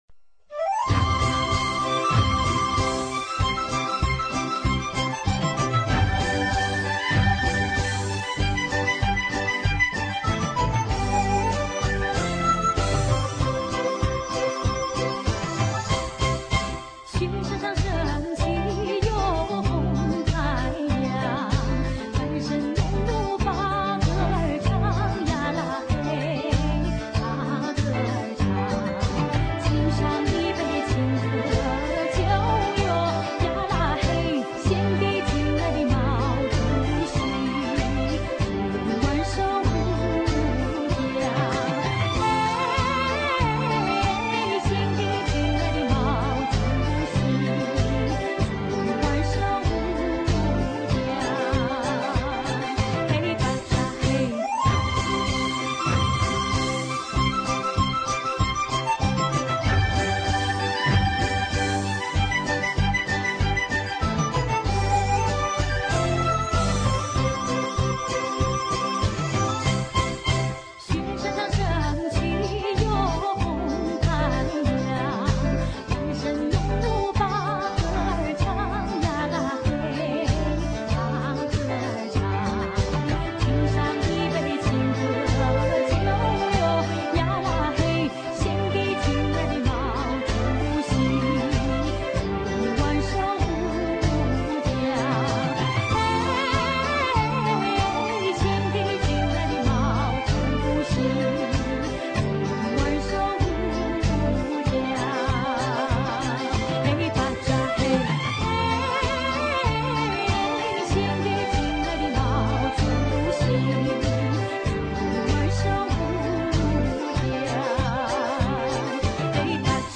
就是这首，我发的这个显然不是原唱。